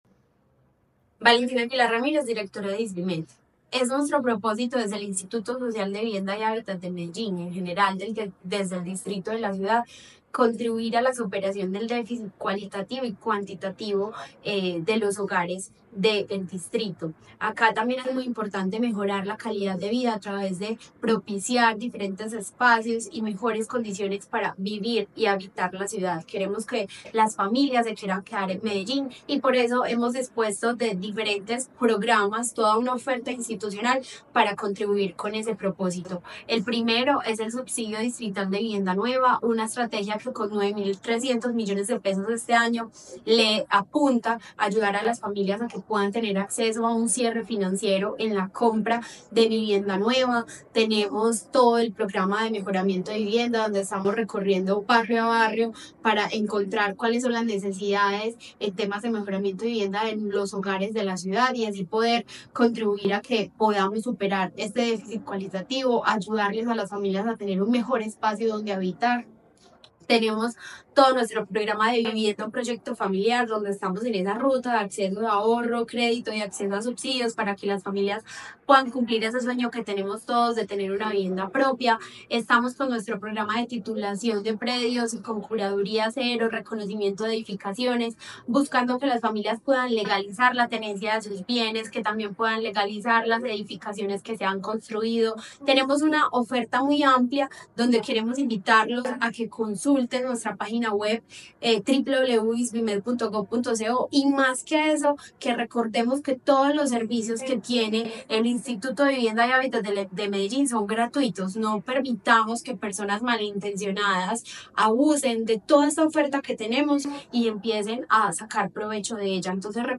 Audio Palabras de Valentina Aguilar Ramírez, directora del Isvimed La Administración Distrital trabaja para garantizar las condiciones habitacionales de la ciudadanía, a través de diferentes programas y estrategias.